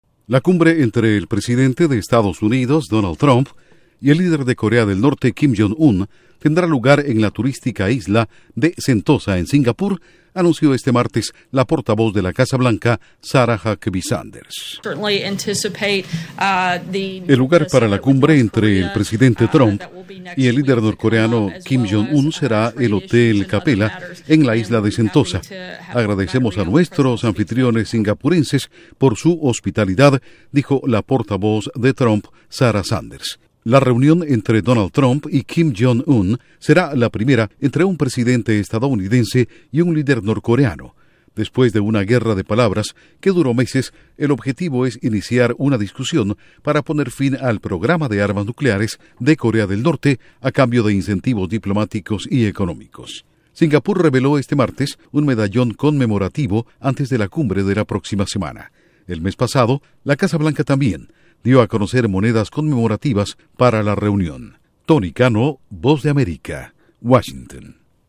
1 audio de la portavoz de la Casa Blanca